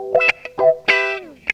GTR 49 EM.wav